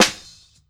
Fly Snare.wav